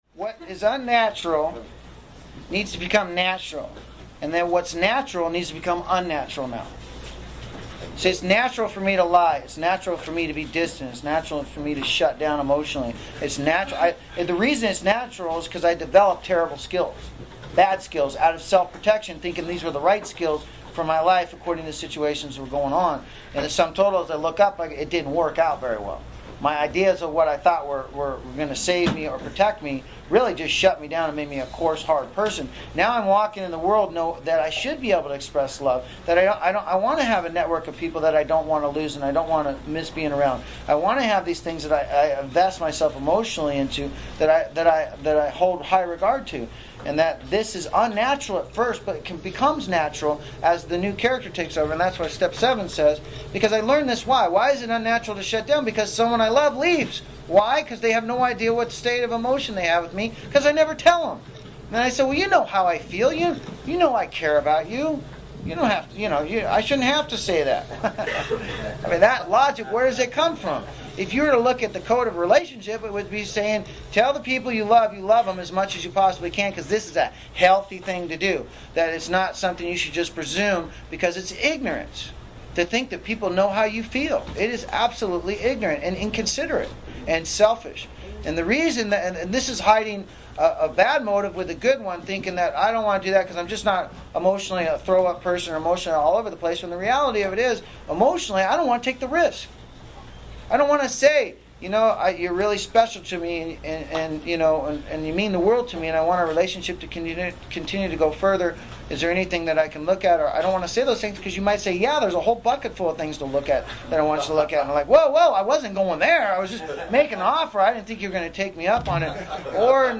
A capture of an honest, heartfelt discussion about the ongoing journey of self-improvement, emotional maturity, and spiritual growth. In this meeting, participants openly share their experiences with the challenges of humility, emotional detachment, and the need for self-reflection.